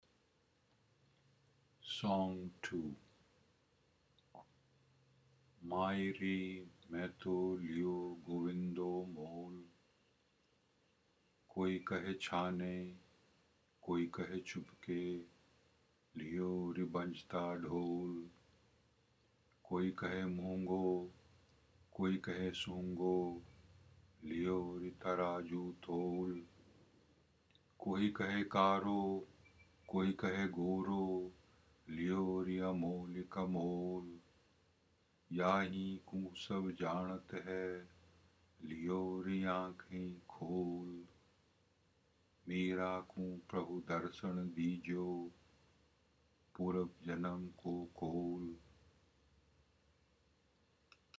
A reading of Song 2 in Hindi